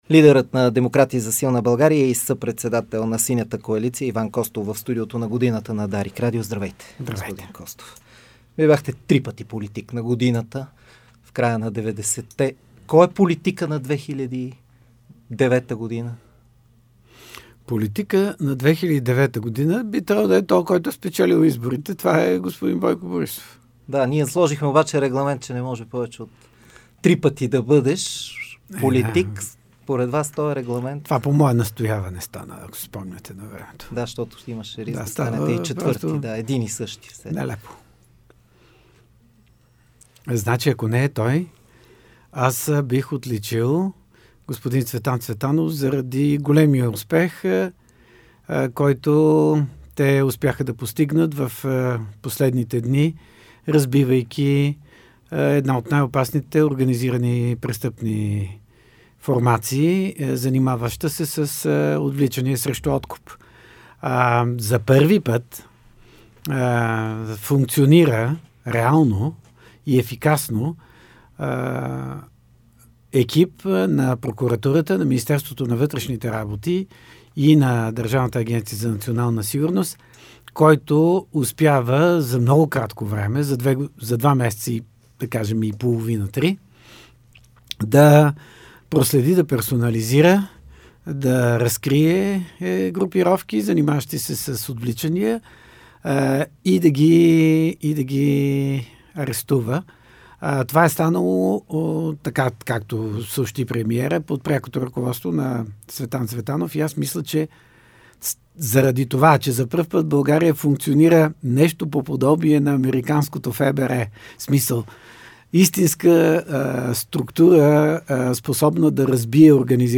Интервю с Иван Костов